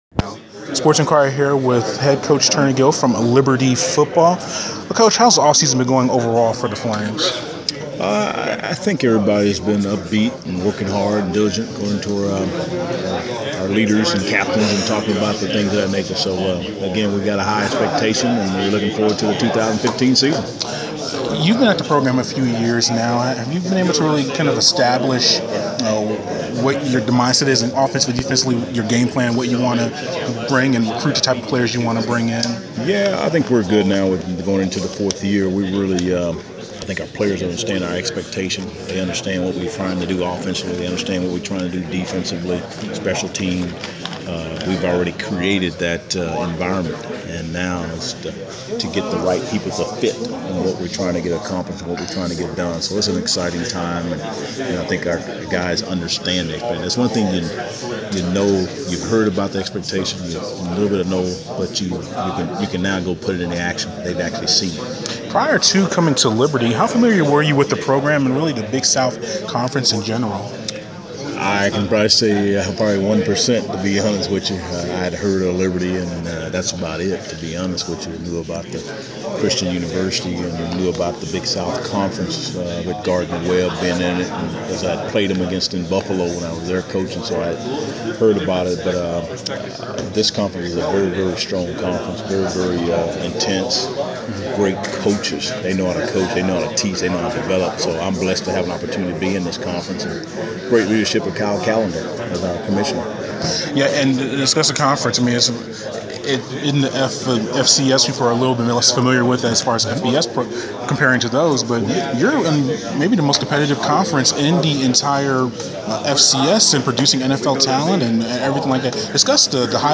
Interview
at the Big South Media Day event in Atlanta